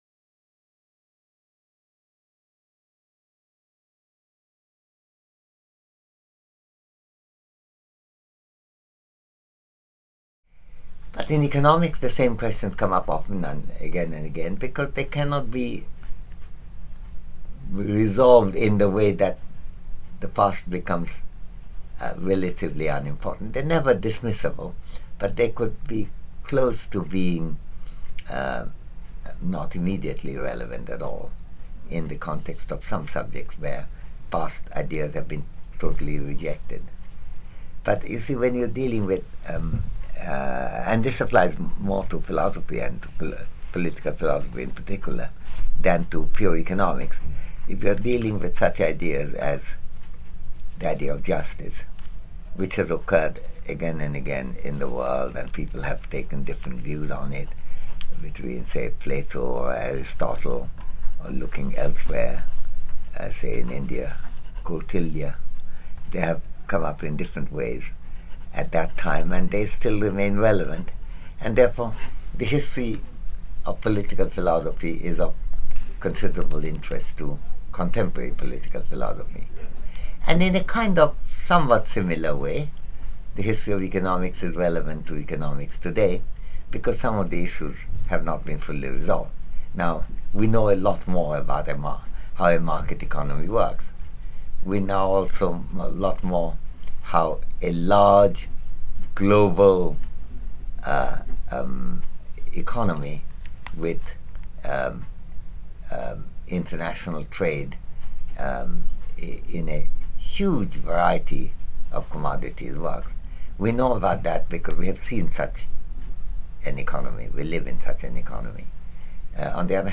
A conversation with Amartya Sen | Canal U